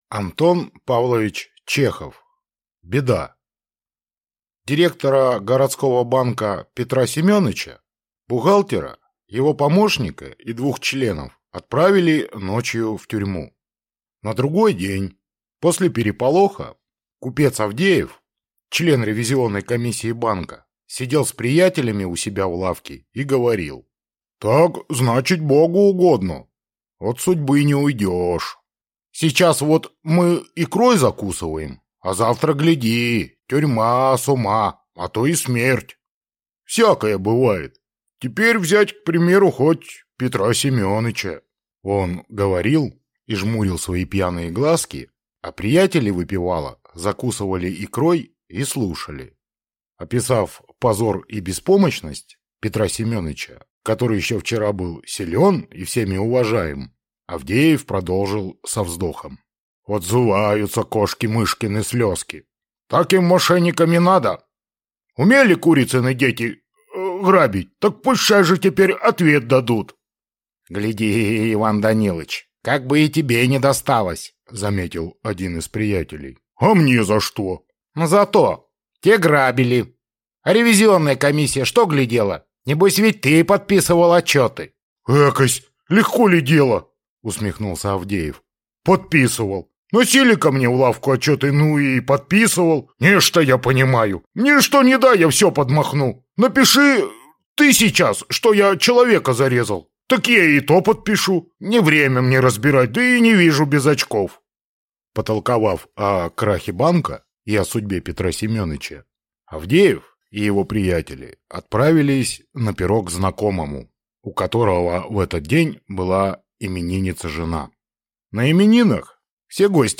Aудиокнига Беда Автор Антон Чехов Читает аудиокнигу Артист. Прослушать и бесплатно скачать фрагмент аудиокниги